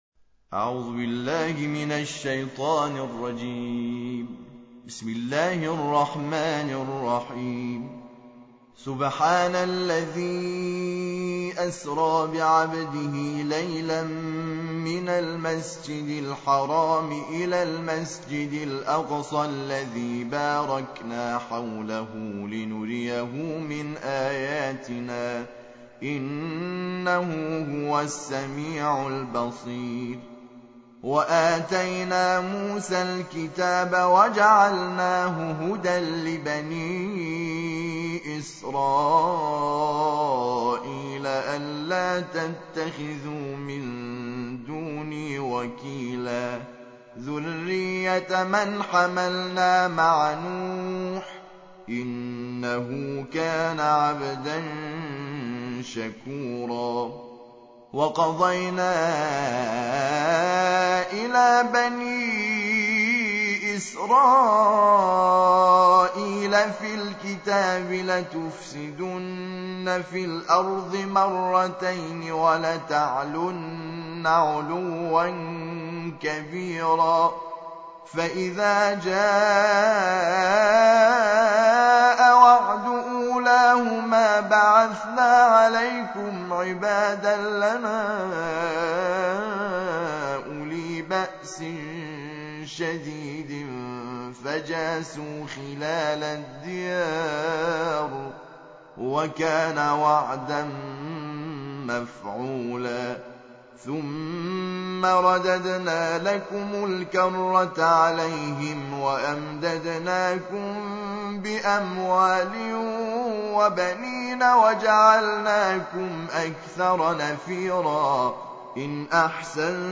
تحميل : الجزء الخامس عشر / القارئ حامد شاكر نجاد / القرآن الكريم / موقع يا حسين